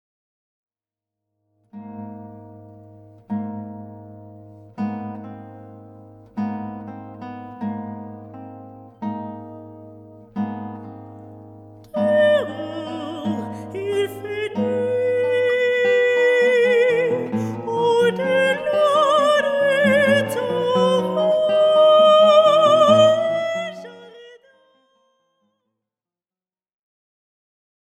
Chants sacrés
La rencontre de la guitare et de la voix.
accompagnées à la guitare